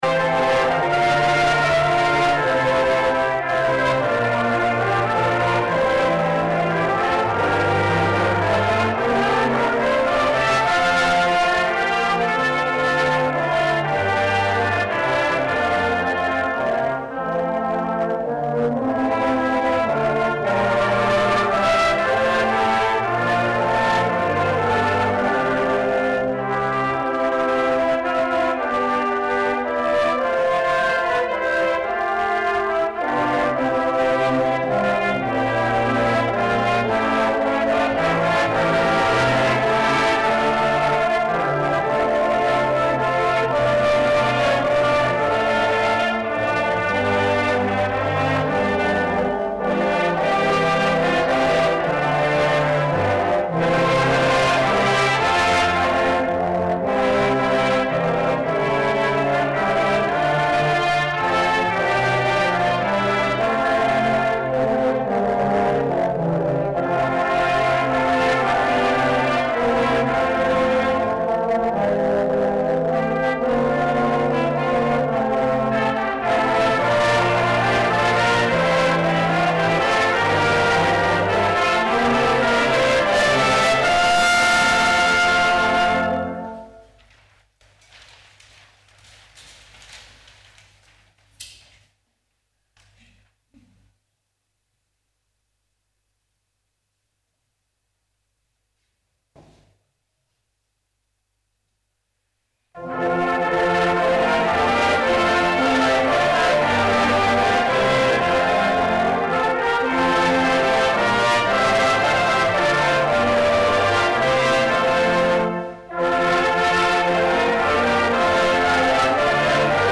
Gottesdienst - 30.11.2025 ~ Peter und Paul Gottesdienst-Podcast Podcast